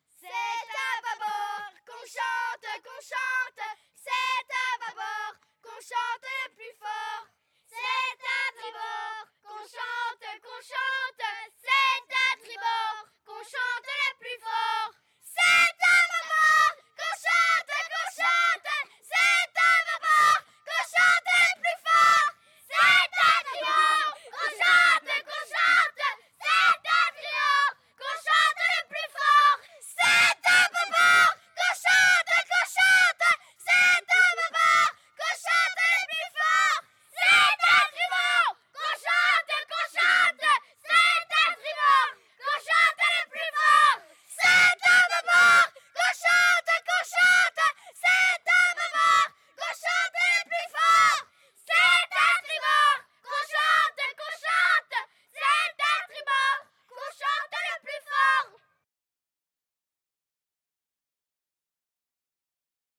Redécouvrir les musiques traditionnelles de Wallonie
Enregistrements Allons la mère Gaspard Chanson incomplète.